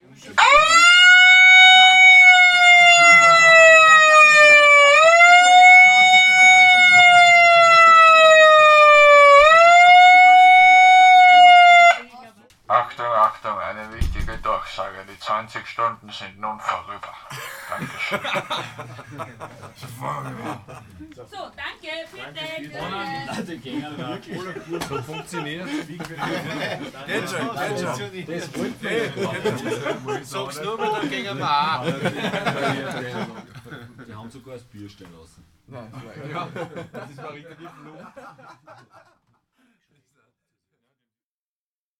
Konzertende im Proberaum (mp3)